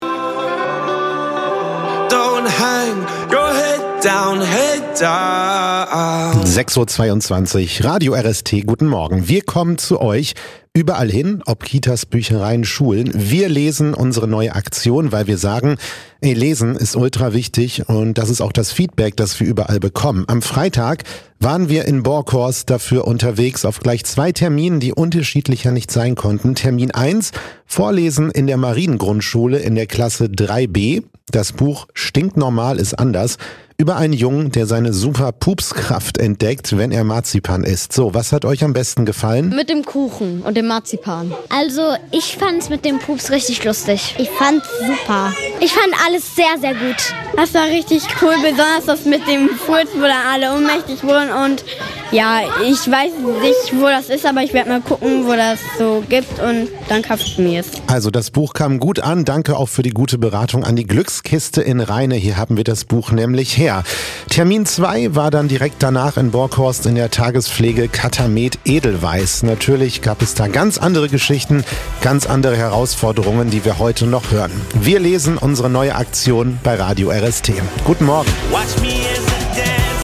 Der Radiobeitrag als Mitschnitt